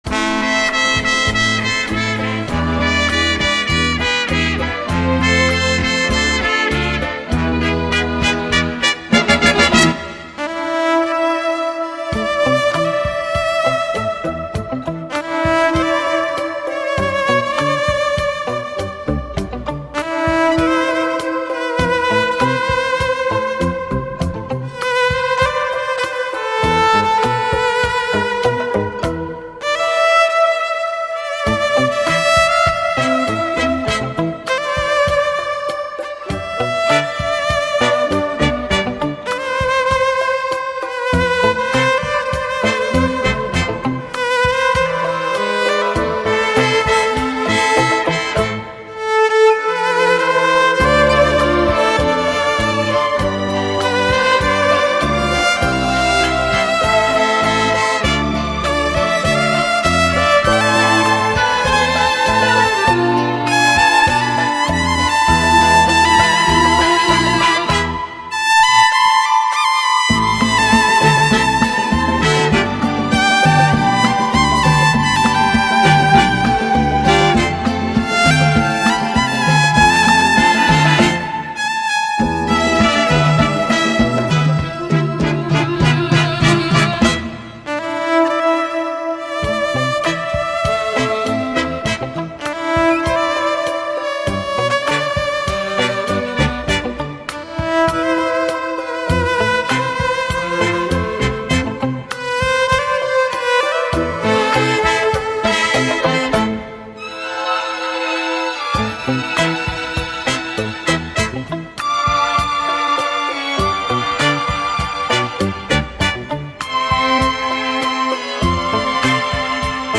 바이올린